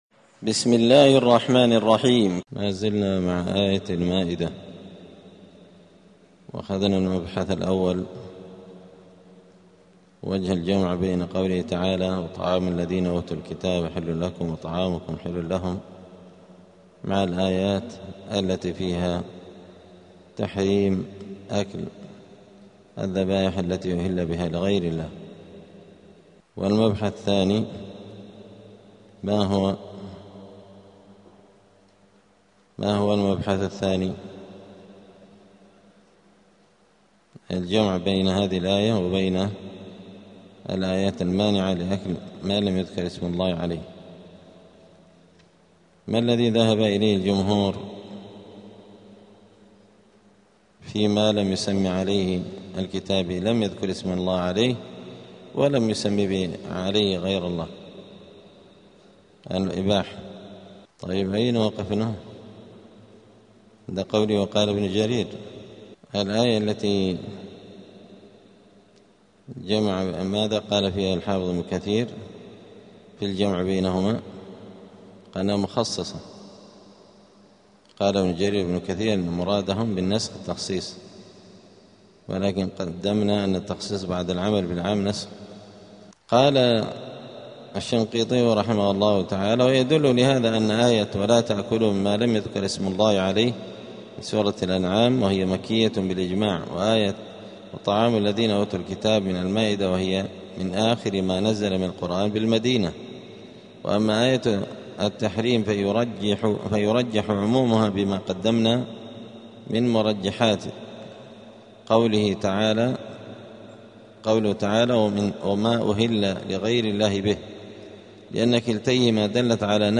*الدرس الثلاثون (30) {سورة المائدة}.*